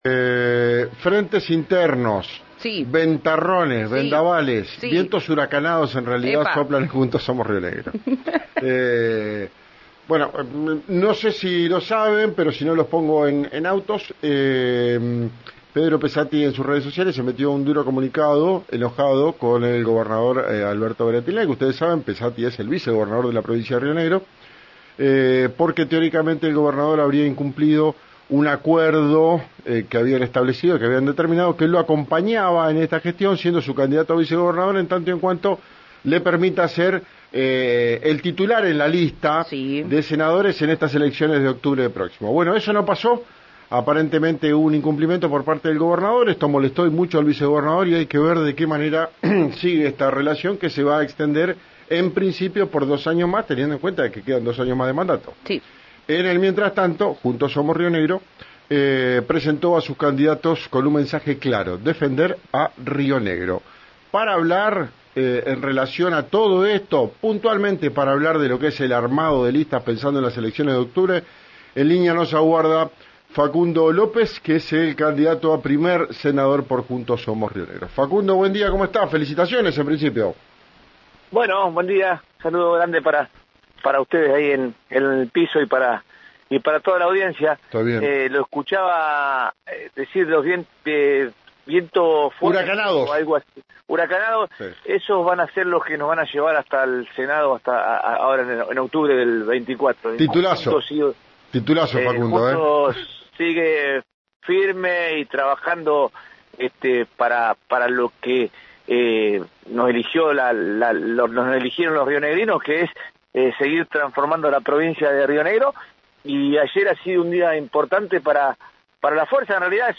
Escuchá al candidato a senador por JSRN, Facundo López , en RÍO NEGRO RADIO